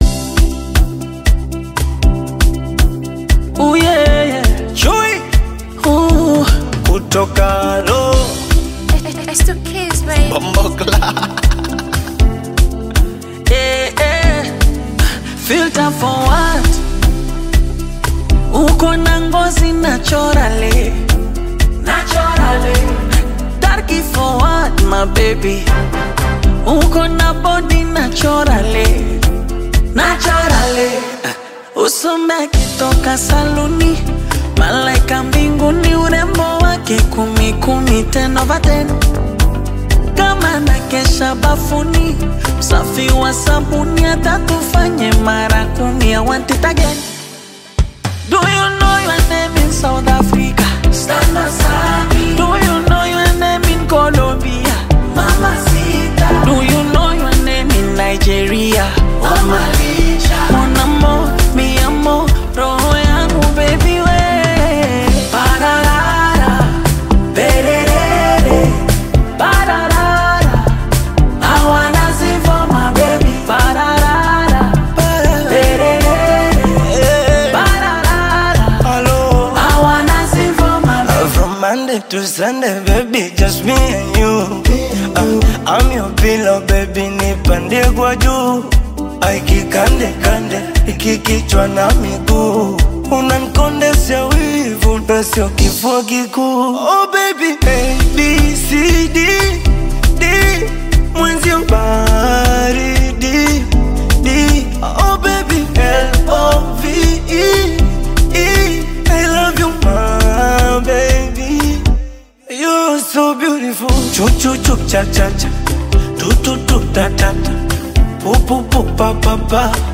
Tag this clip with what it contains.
Genre: Singeli